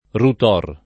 [ rut 0 r ]